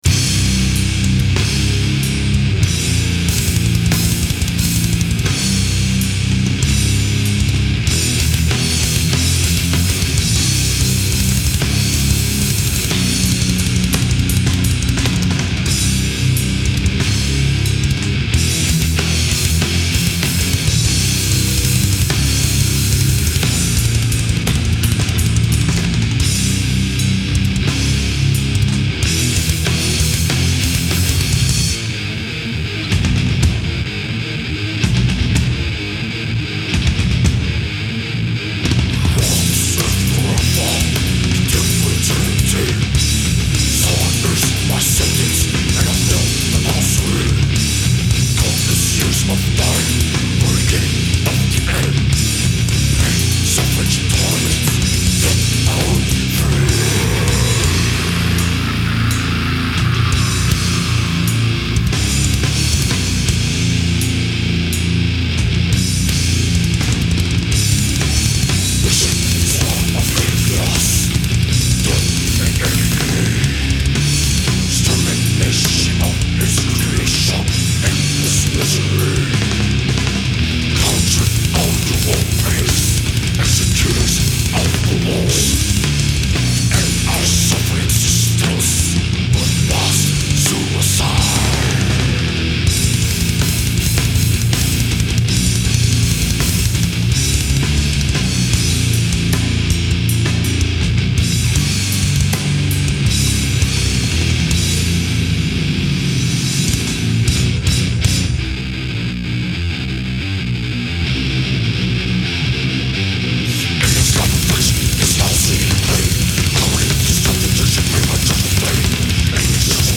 Brutal Death Metal Unleashed